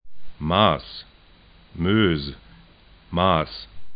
ma:s